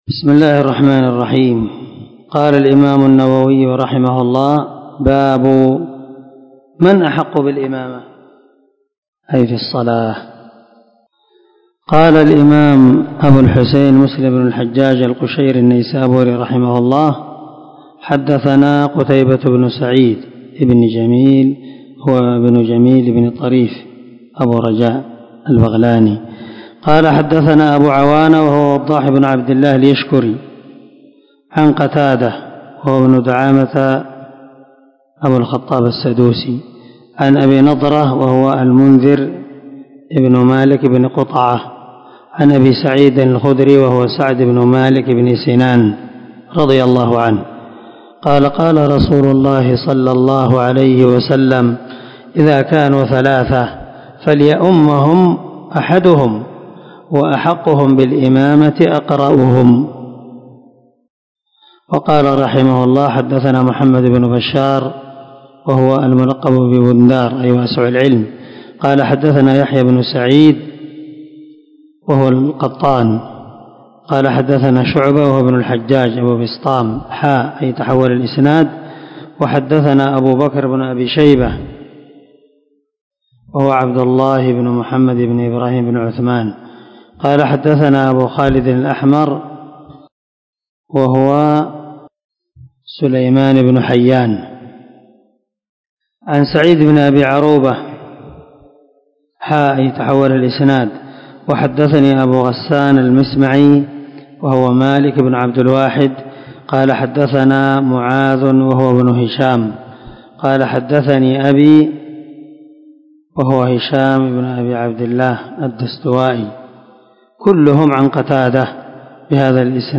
دار الحديث- المَحاوِلة-